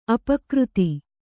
♪ apakřti